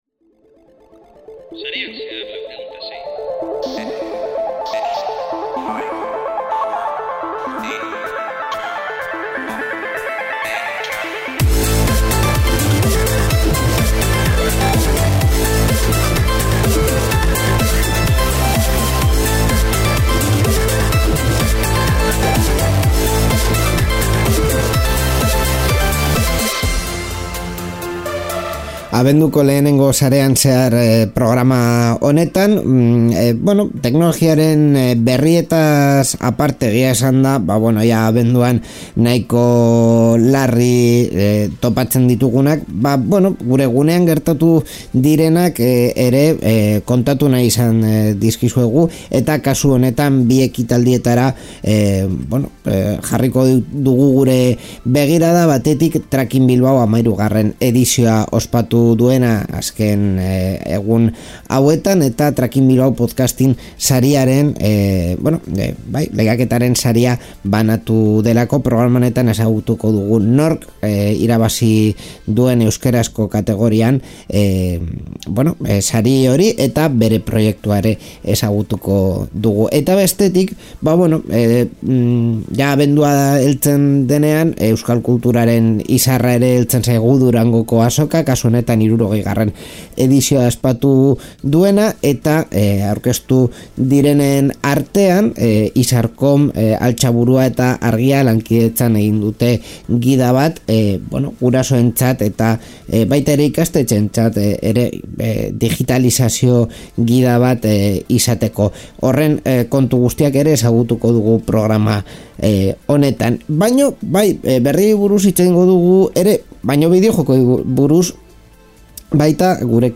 Tracking Bilbaorekin jarraituko dugu, Bilboko zabalguneko merkatuan egin zen ekitaldi batean, Tracking Bilbao Podcasting sariak banatu baitzituzten.